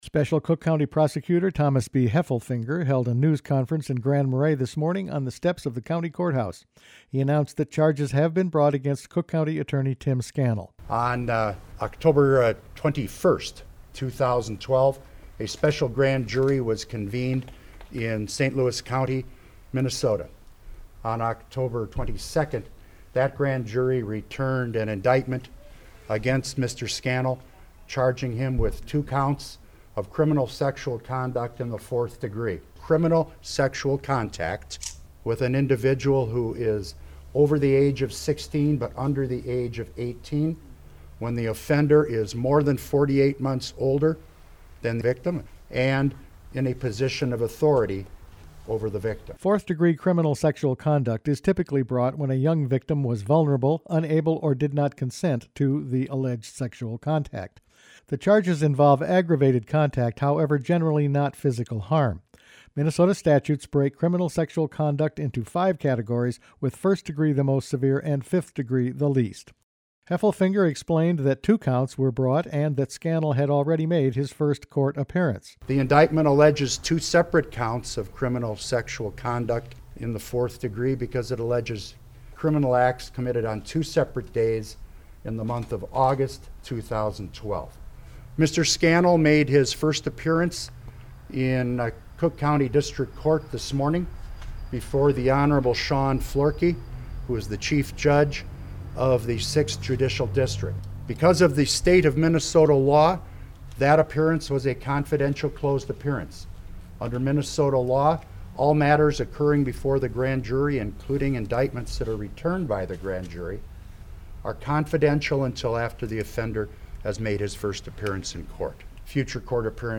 Special Cook County Prosecutor Thomas B. Heffelfinger held a news conference in Grand Marais this morning on the steps of the county courthouse.